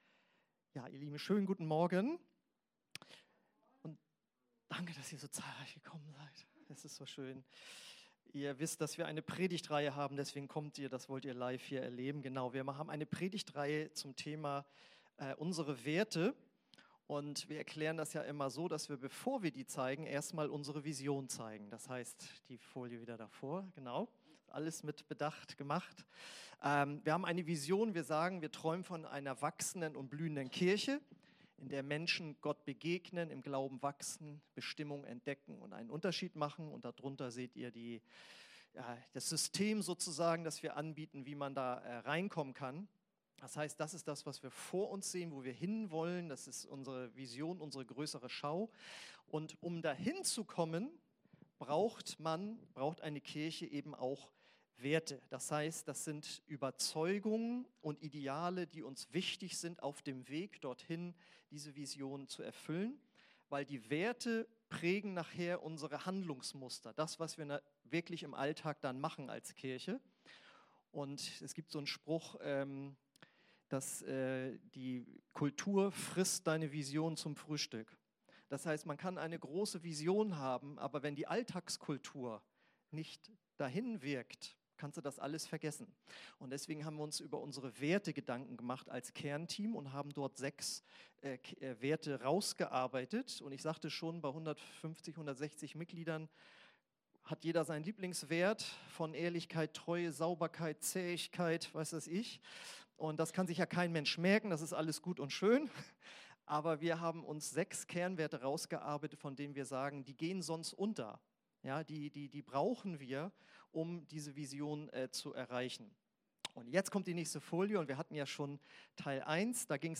Eine predigt aus der predigtreihe "Unsere Werte."